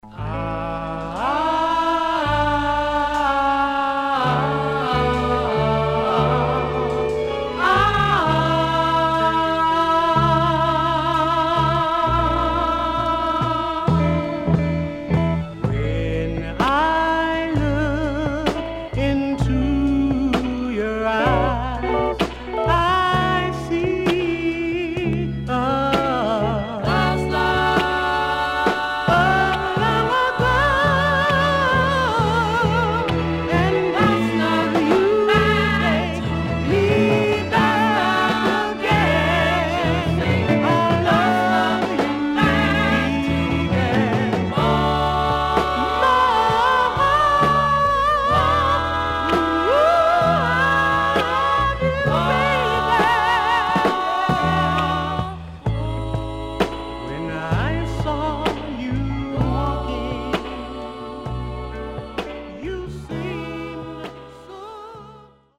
HOME > Back Order [SOUL / OTHERS]
ファルセットが素晴らしいW-Side Goodな1枚。005
SIDE A:少しチリノイズ入りますが良好です。